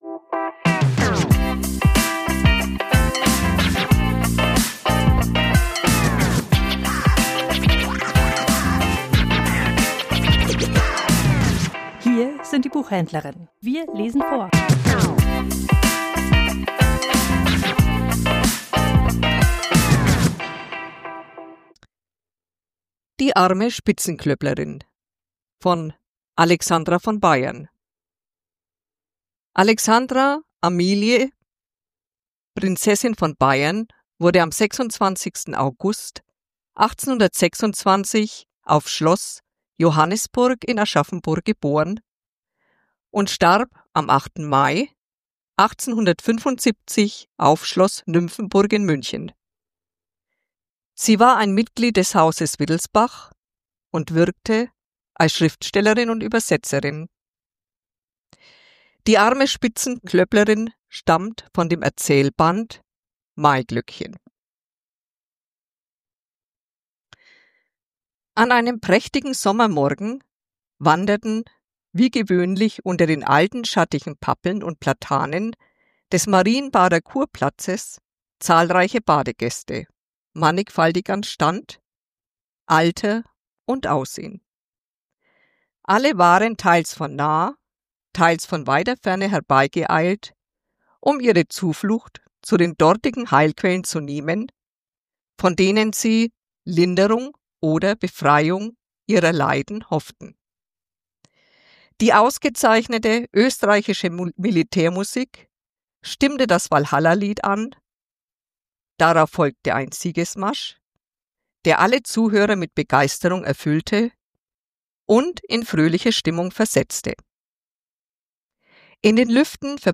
Vorgelesen: Die arme Spitzenklöpplerin ~ Die Buchhändlerinnen Podcast